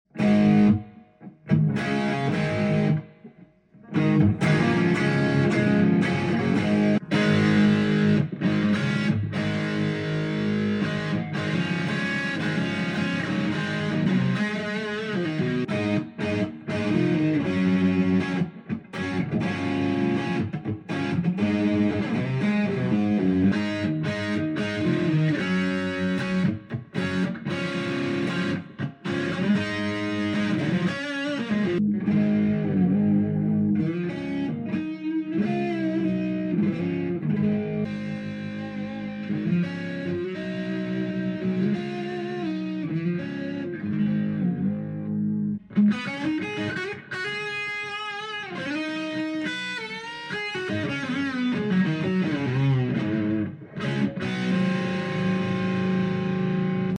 Sound Sample Before and After sound effects free download
Sound Sample Before and After Pro Setup Tremolo Bridge Guitar Standard Fender Limited Edition Mahogany Blacktop Stratocaster HH Crimson Red
* Enhanced sustain and clarity
* Reduced string buzz and noise